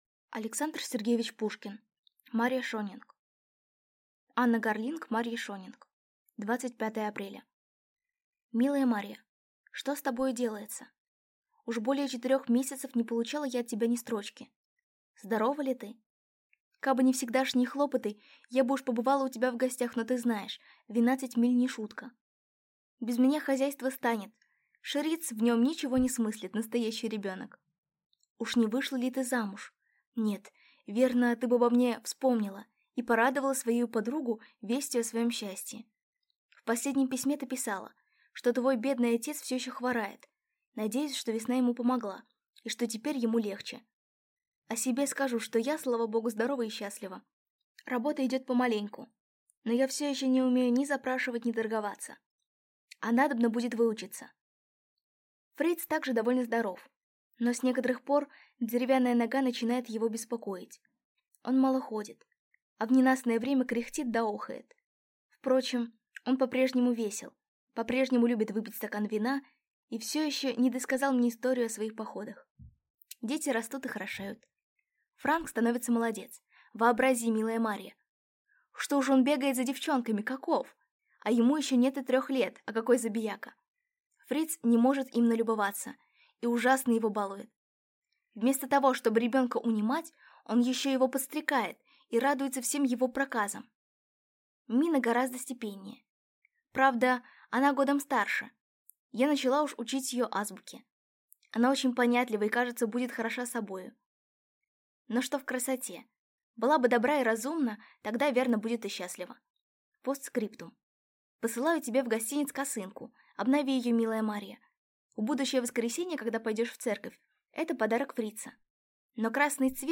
Аудиокнига Марья Шонинг | Библиотека аудиокниг